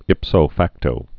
(ĭpsō făktō)